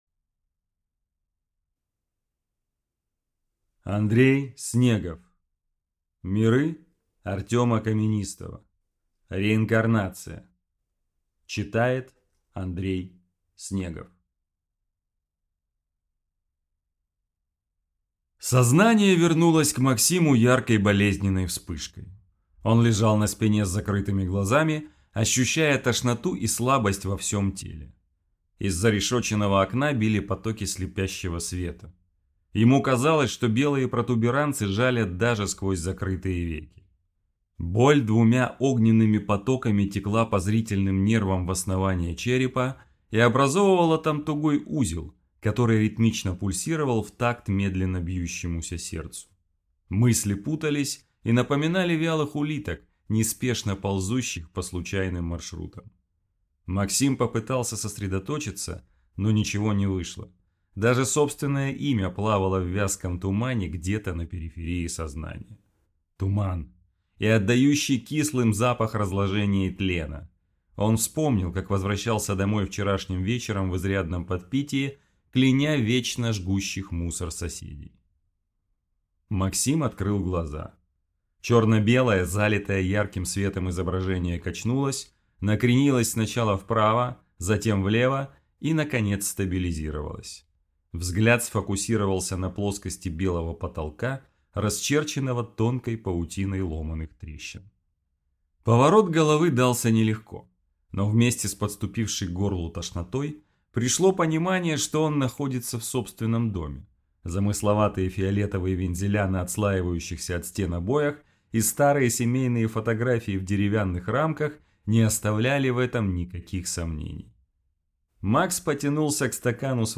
Аудиокнига S-T-I-K-S. Реинкарнация | Библиотека аудиокниг